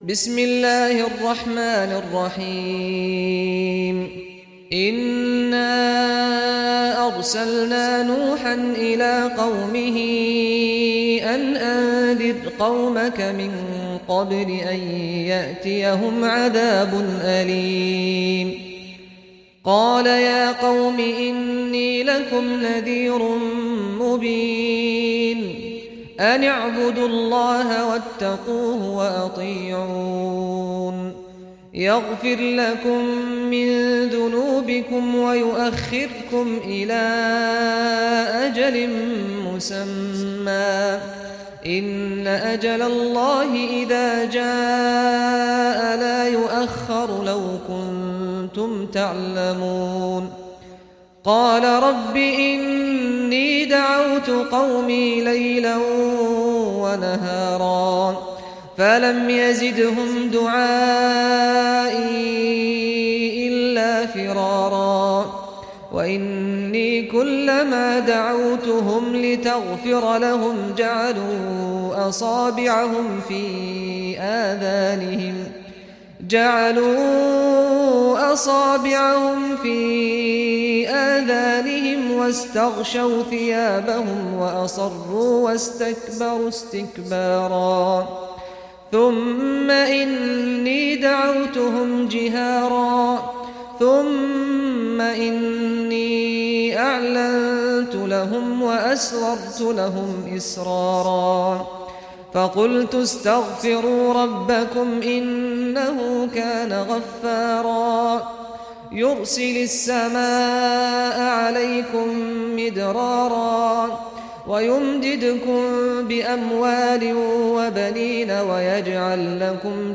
Surah Nuh Recitation by Sheikh Saad al Ghamdi
Surah Nuh, listen or play online mp3 tilawat / recitation in Arabic in the beautiful voice of Sheikh Saad al Ghamdi.